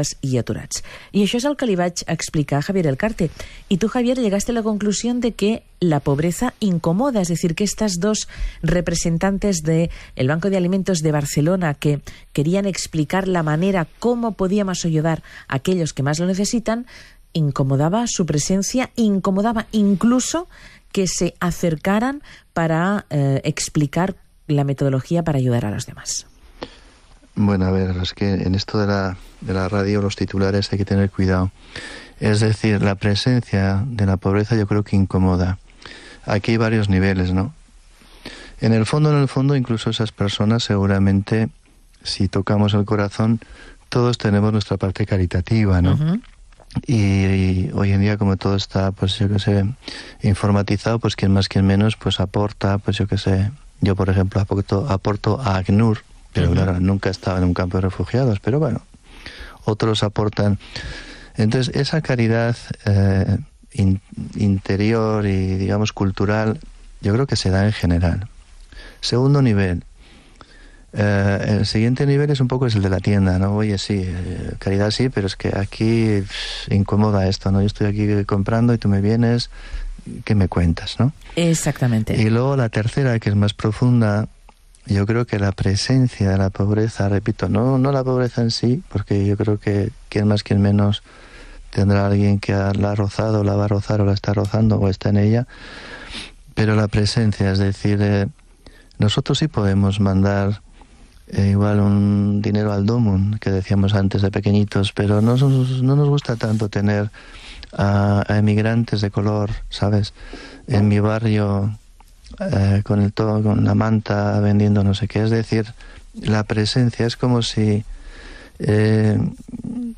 Para escuchar la entrevista completa en castellano, pulsa play en el siguiente enlace: